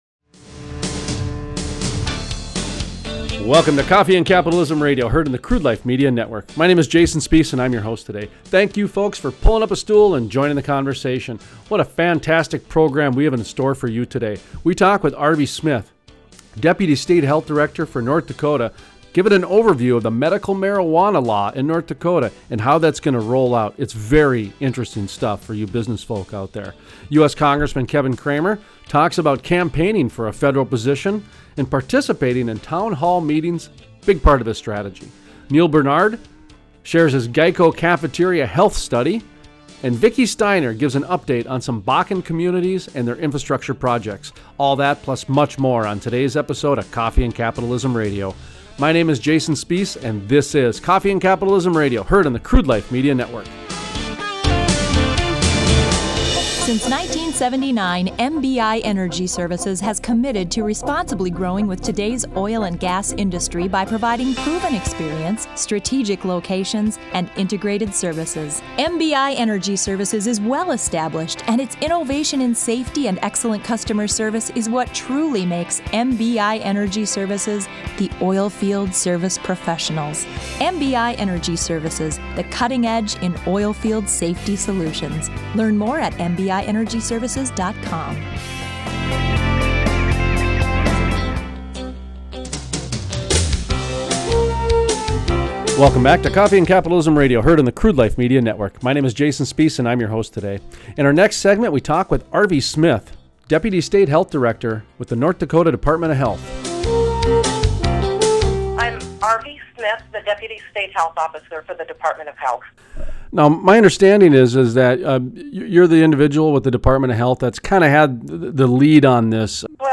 Interview: Arvy Smith, Deputy State Health Officer, ND Dept of Health Gives an overview of how the medical marijuana laws in North Dakota will work and what businesses will be needed for the new industry.